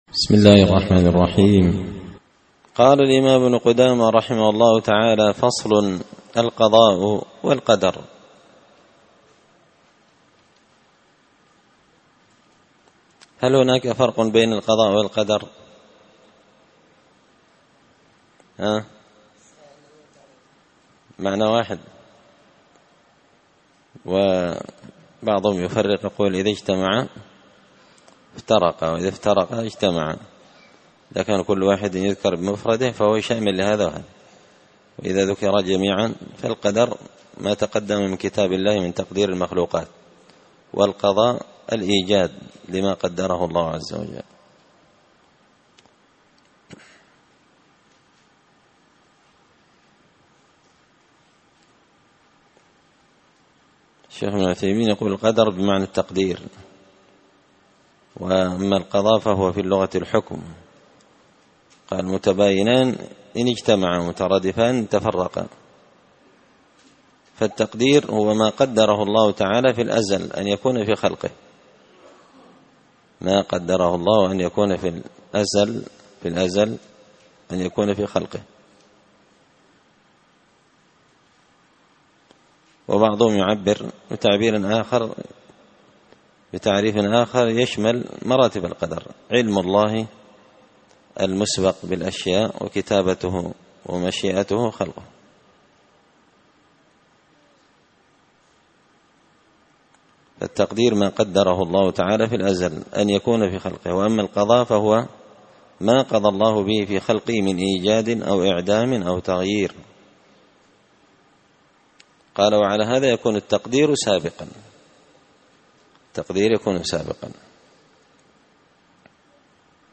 شرح لمعة الاعتقاد ـ الدرس 27
دار الحديث بمسجد الفرقان ـ قشن ـ المهرة ـ اليمن